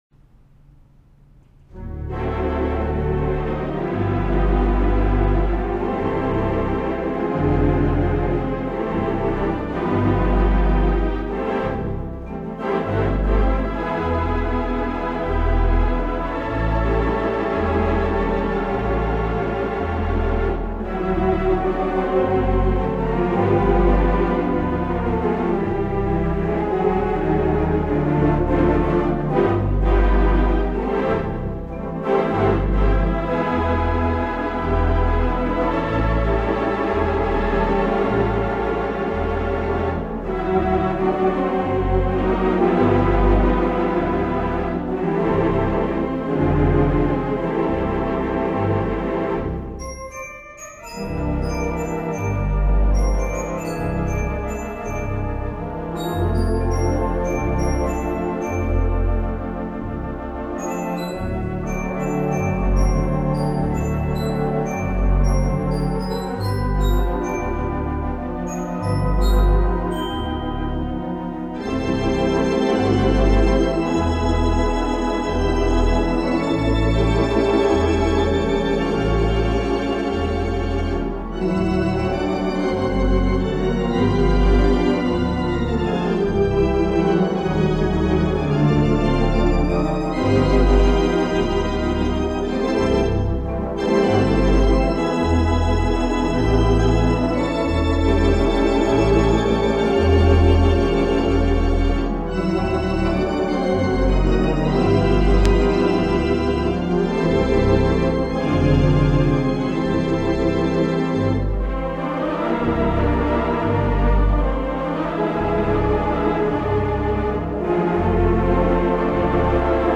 installed at Grace Baptist Church in Sarasota, Florida.
I am not super happy with the sound.